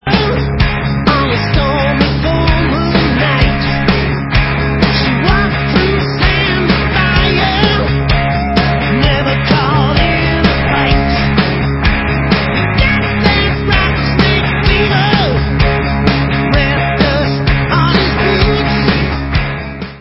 rocková kapela
studiové album